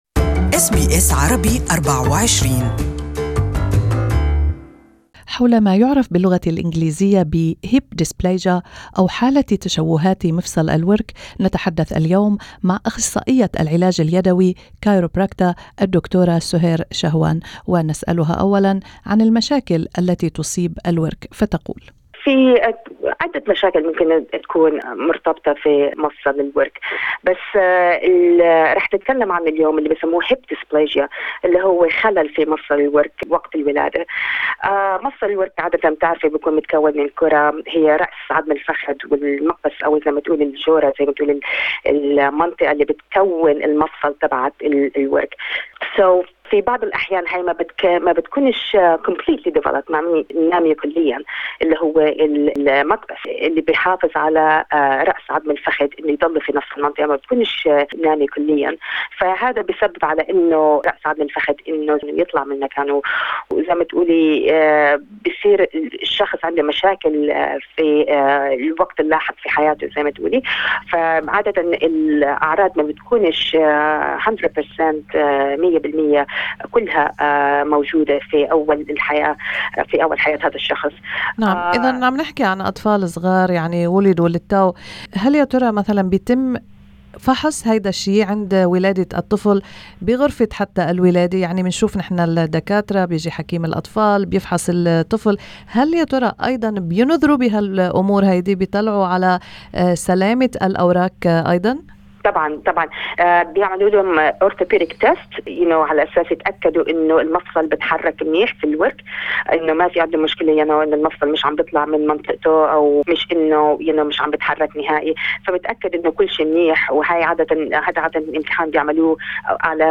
في هذا اللقاء